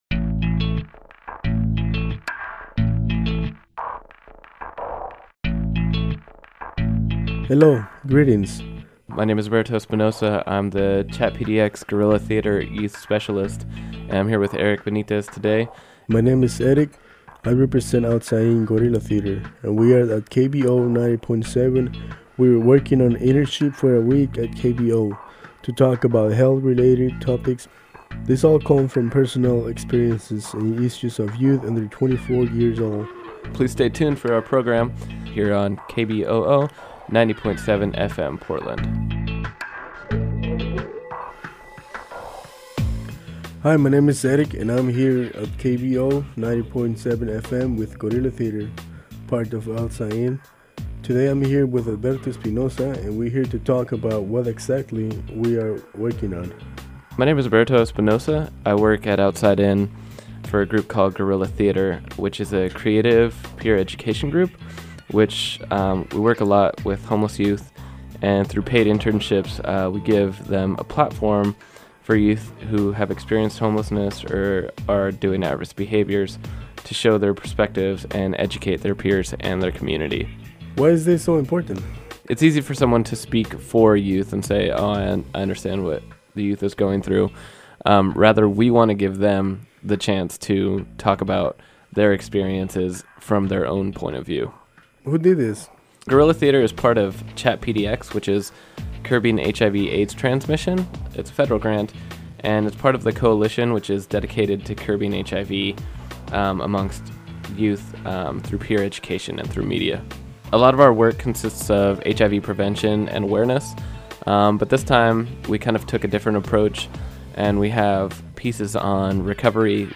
Outside In Guerilla Theatre Peer Education Radio